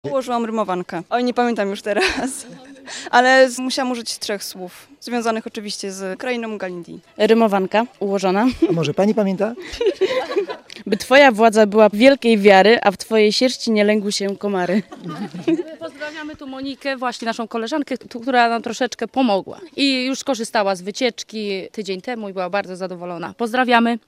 Słuchacze 1